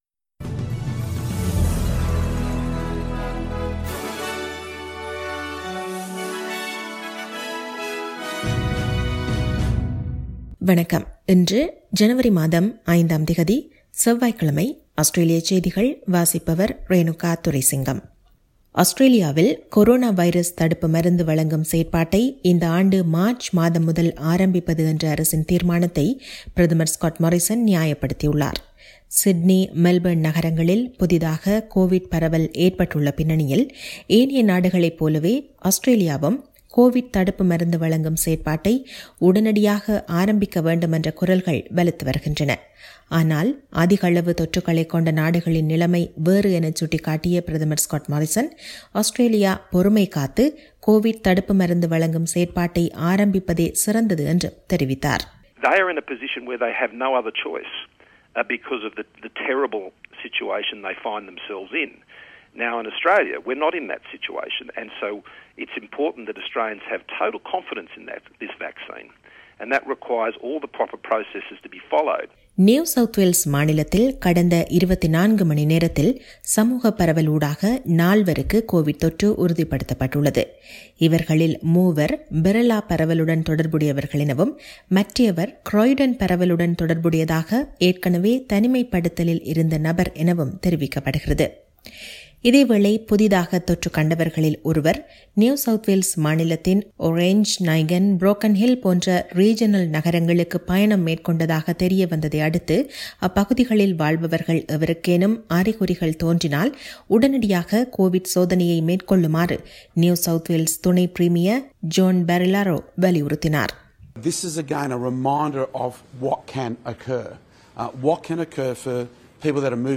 Australian news bulletin for Tuesday 05 January 2021.